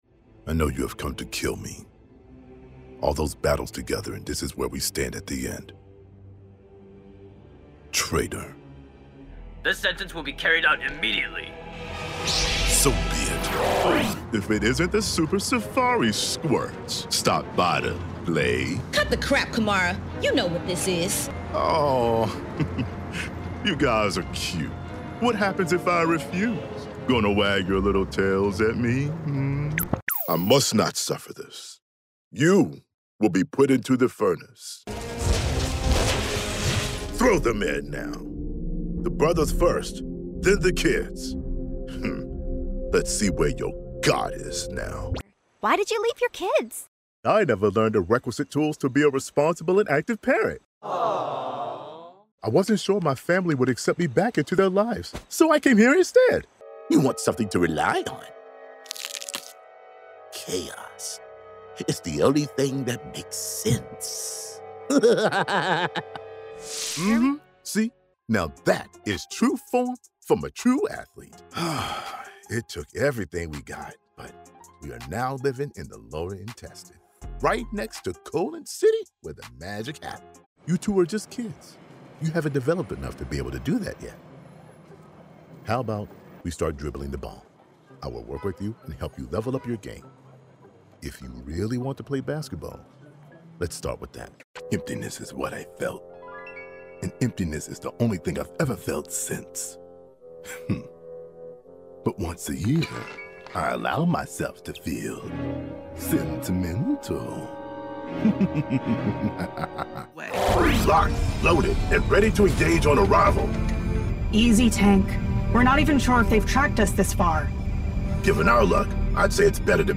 CHARACTER DEMO REEL